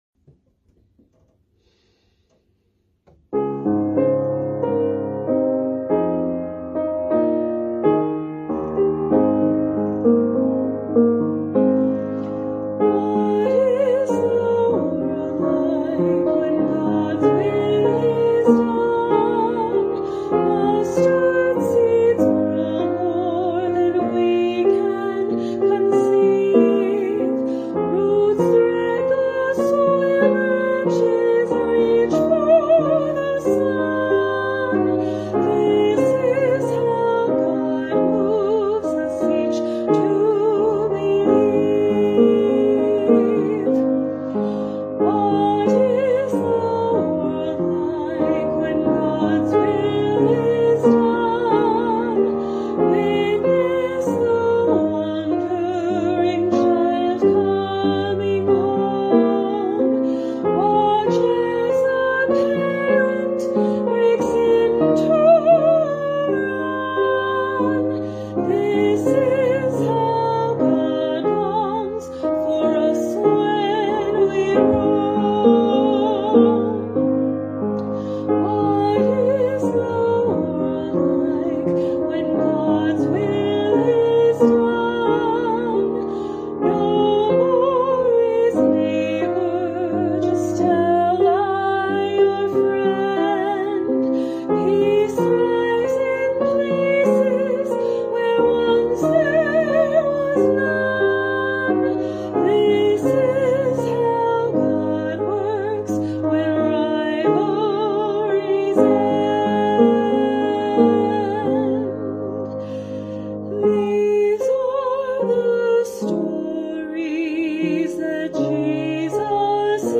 HYMN: Adam M.L. Tice ©